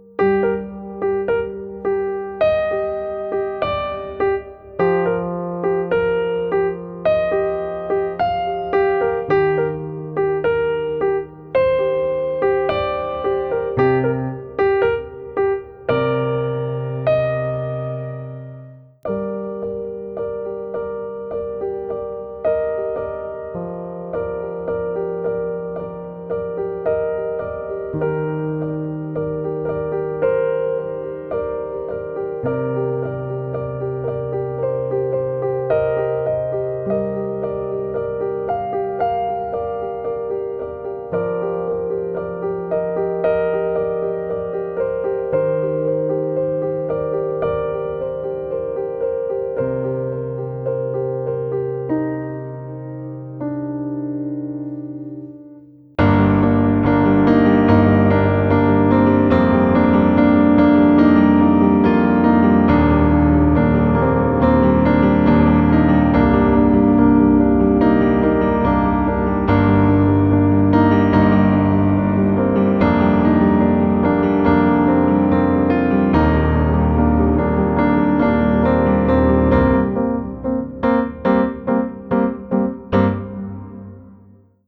A new piano composition.